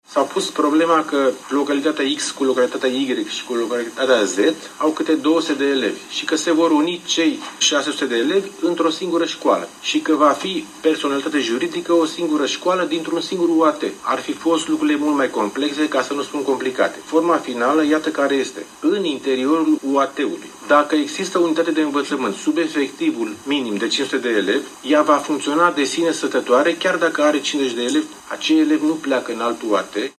Elevii nu se mută însă în alte localități, a explicat prefectul judetului, Ion Ghimpeţeanu